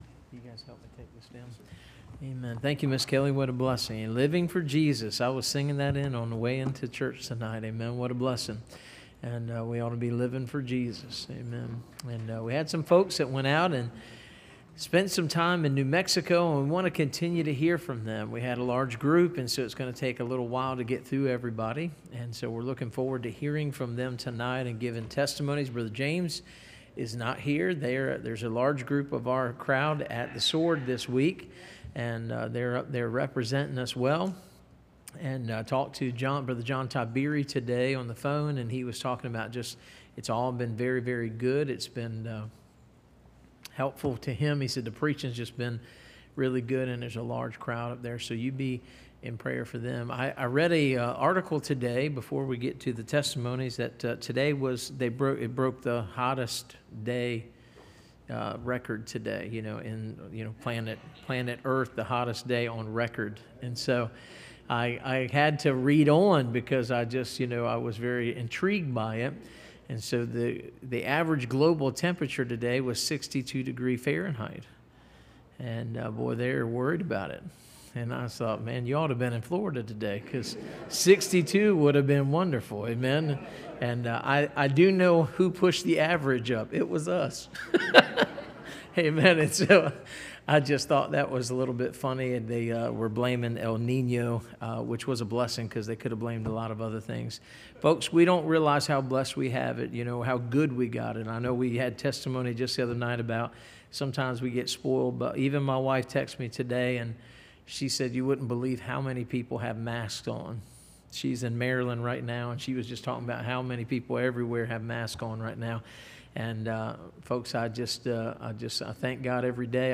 Series: Navajo Indians Trip Testimonies